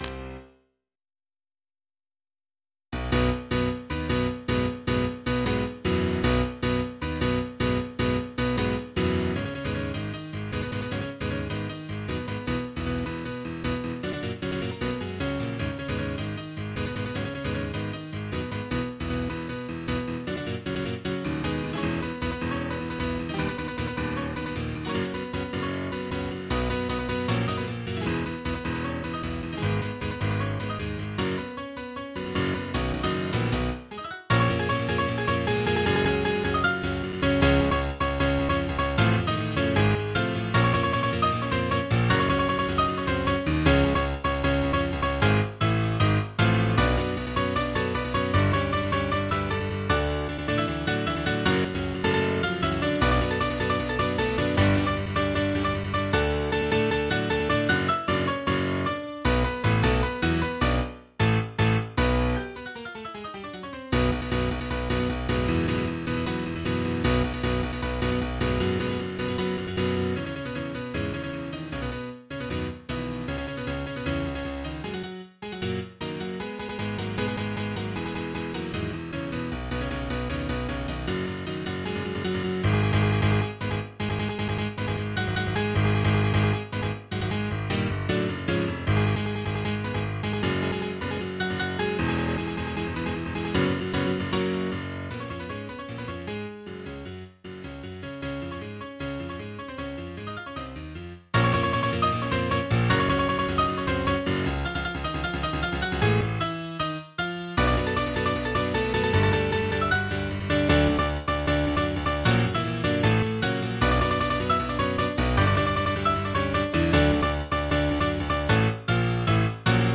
MIDI 54.93 KB MP3